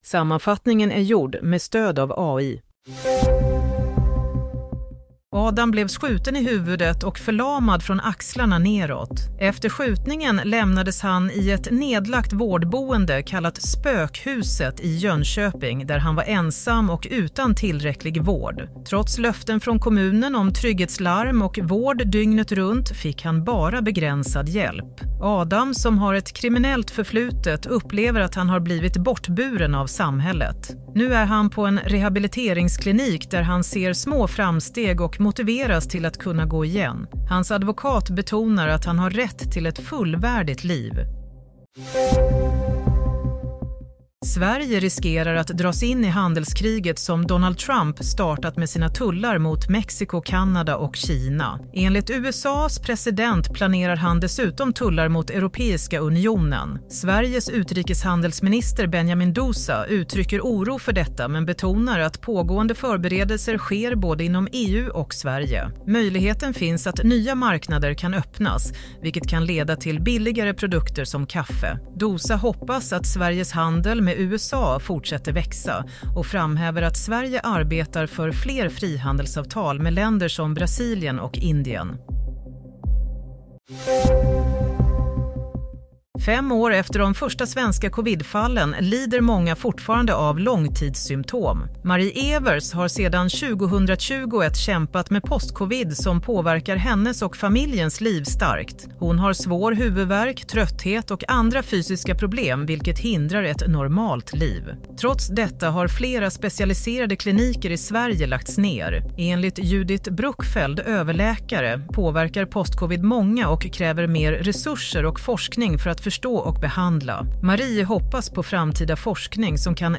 Nyhetssammanfattning – 2 februari 22:40